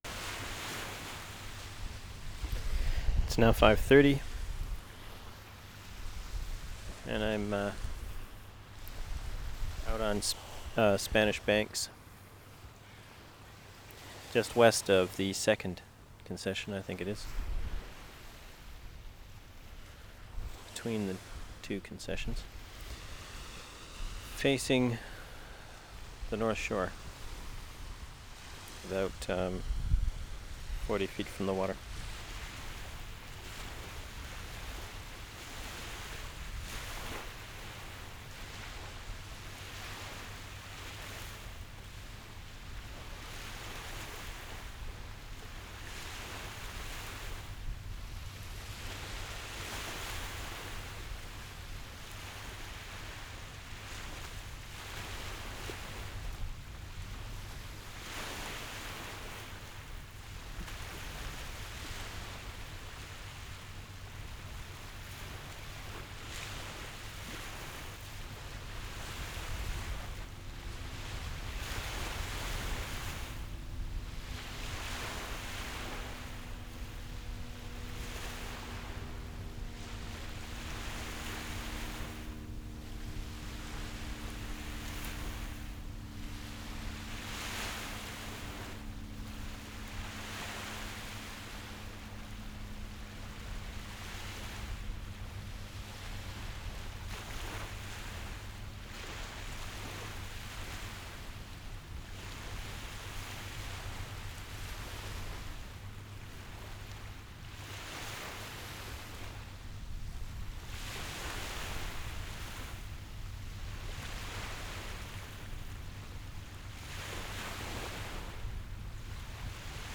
Spanish Banks, west of second concession 8:55
26. ID, waves, wind on mic, plane at 1:11, boat pass at 3:06, footsteps in sand at 6:07 and then in gravel, traffic, keys opening car door